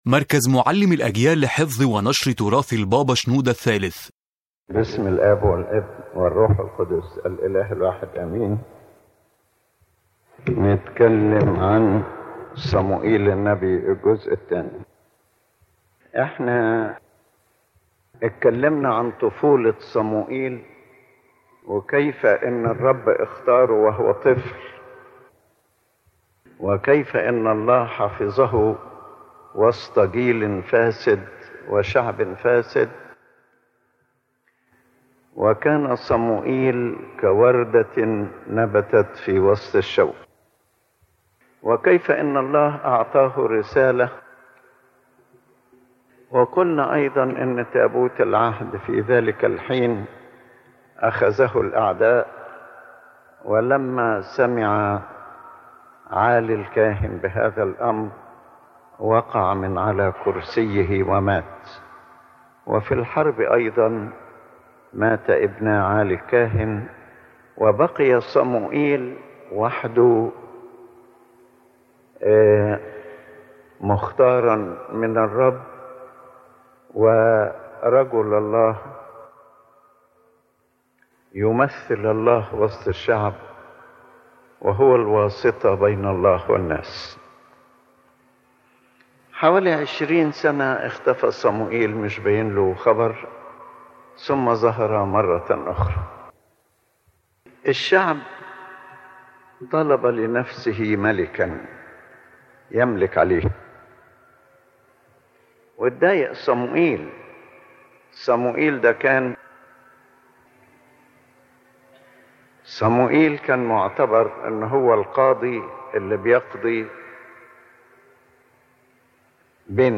The Main Idea of the Lecture